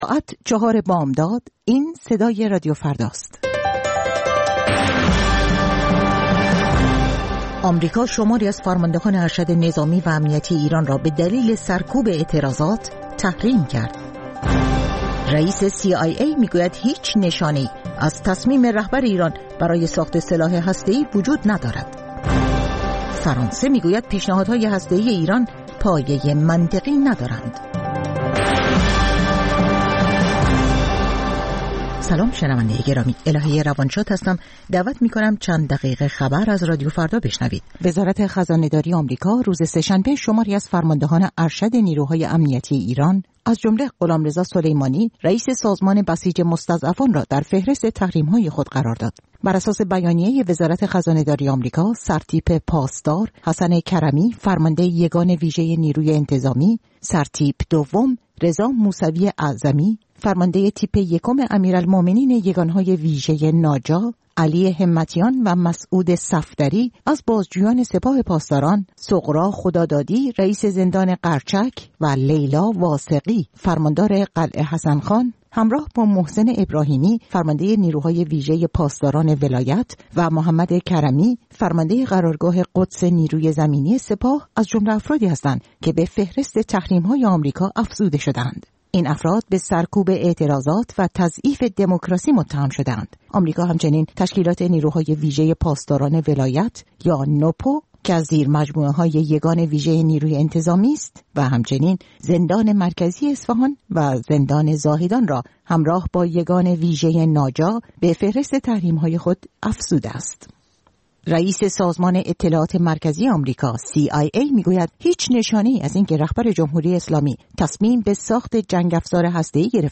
سرخط خبرها ۴:۰۰